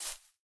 Minecraft Version Minecraft Version 25w18a Latest Release | Latest Snapshot 25w18a / assets / minecraft / sounds / block / azalea / step1.ogg Compare With Compare With Latest Release | Latest Snapshot
step1.ogg